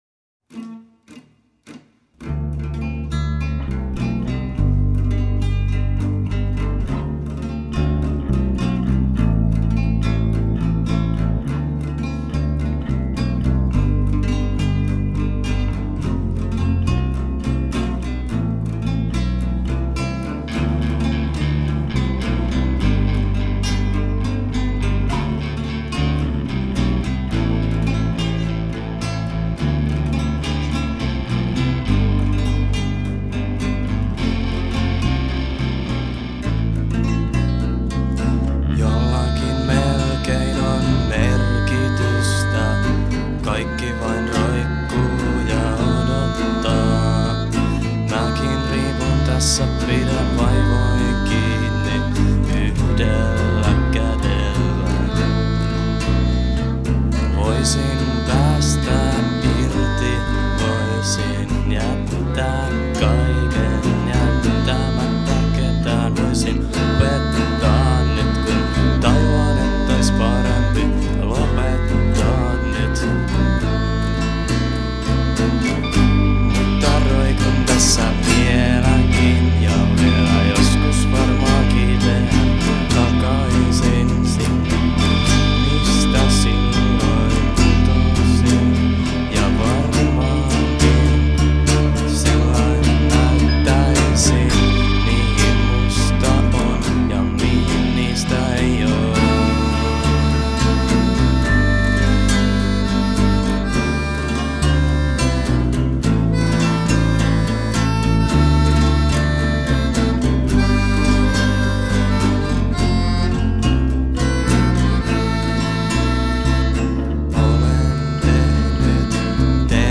>>646244 vähä laulu jää soitinten alle. ihan jännä kyl
>>646247 Laulu saa jäädä soitinten alle.